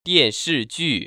[diànshìjù] 띠앤스쥐  ▶